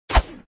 shoot.mp3